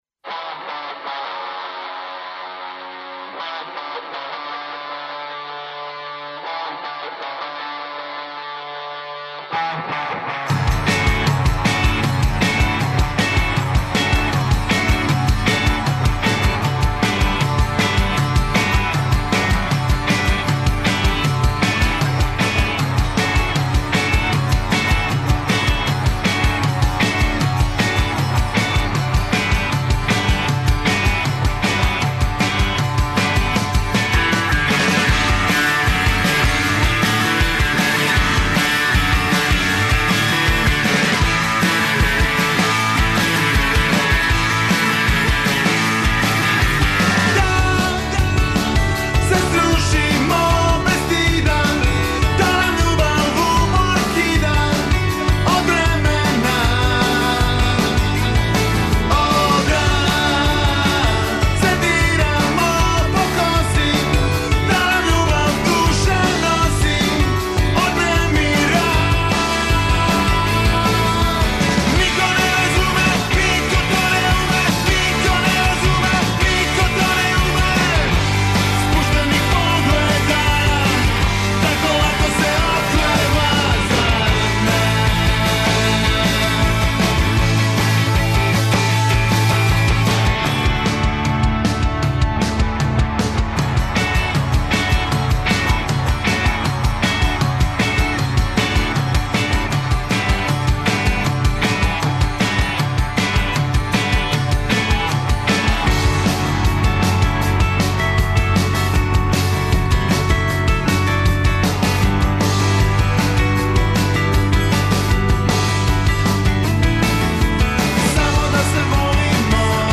Гости емисије су чланови састава E-play.
Емисија из домена популарне културе.